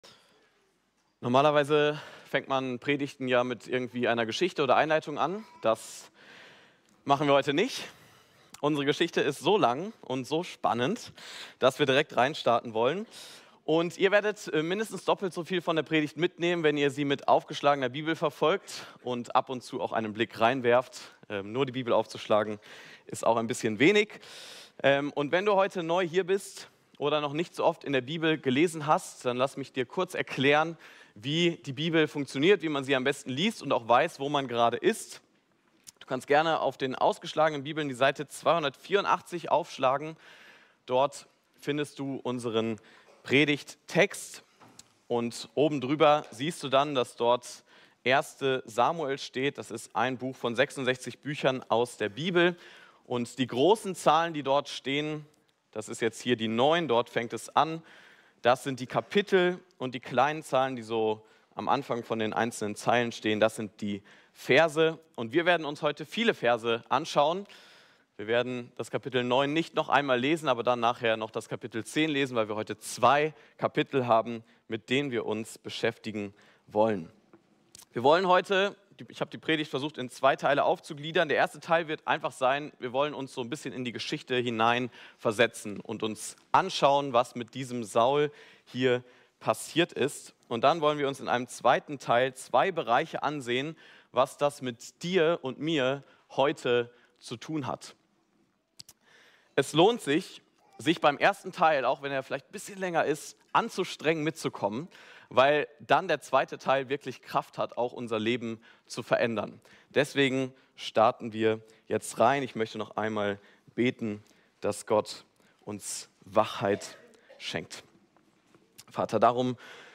FeG München Mitte Predigt Podcast